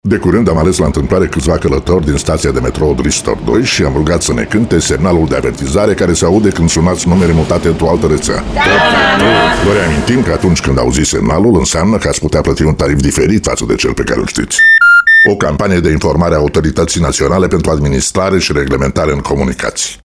- broadcasting radio spots meant to draw the public’s attention on the beep sound and its significance (Kiss FM, Europa FM and Radio Romania Actualitati).